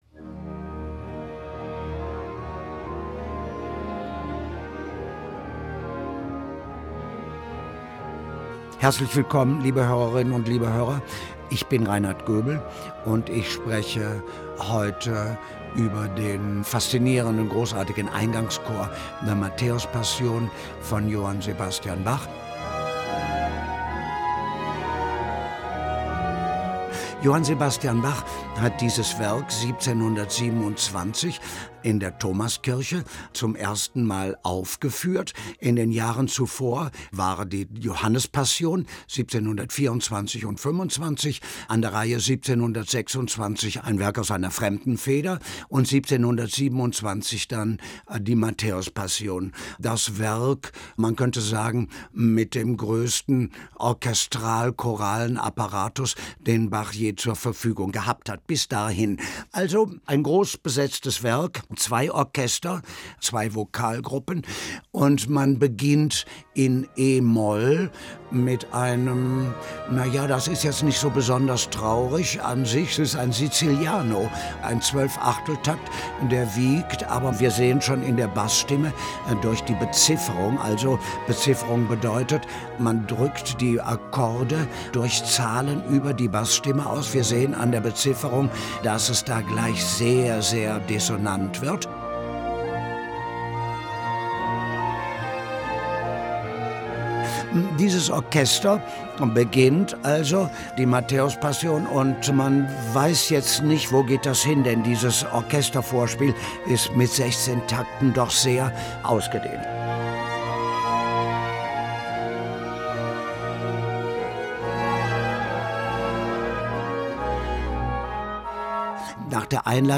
Bachs "Matthäuspassion", erläutert von Reinhard Goebel, Folge 1: Der Eingangschor